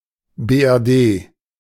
^ German: Bundesrepublik Deutschland [ˈbʊndəsʁepuˌbliːk ˈdɔʏtʃlant] , BRD [ˌbeːʔɛʁˈdeː]
De-BRD.ogg.mp3